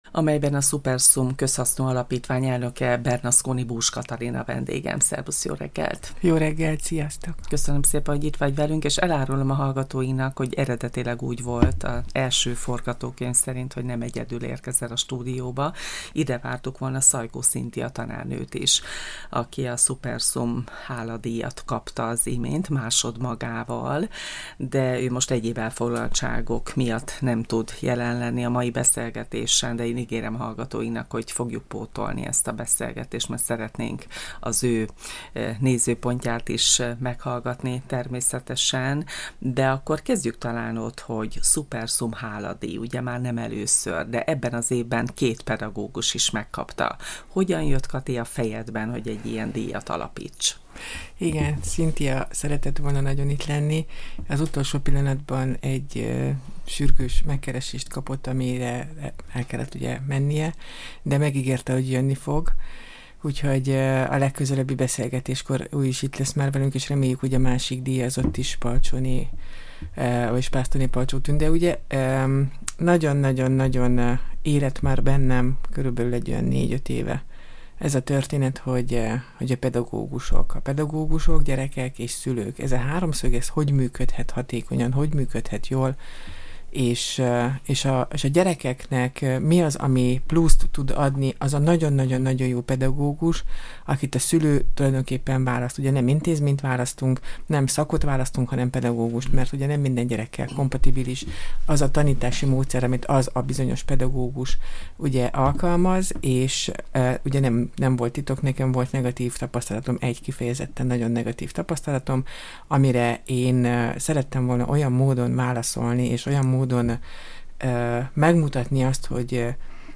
beszélt a Csillagpont Rádióban